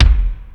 Lotsa Kicks(28).wav